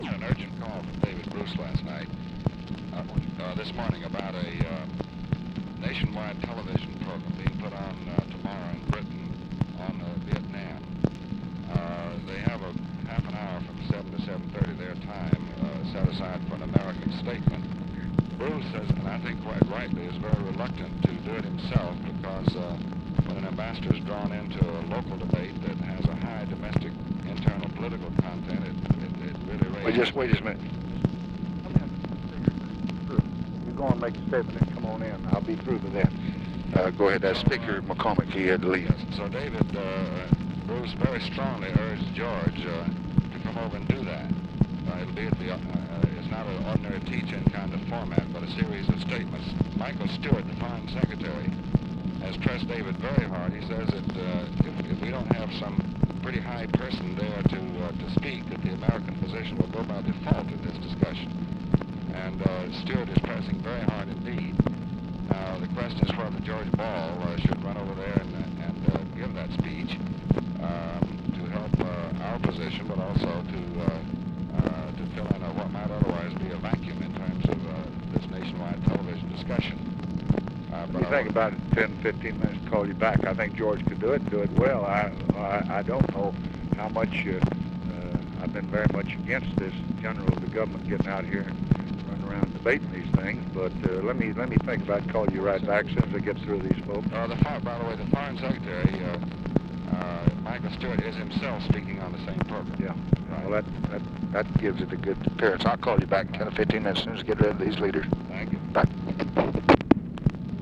Conversation with DEAN RUSK and OFFICE CONVERSATION, June 15, 1965
Secret White House Tapes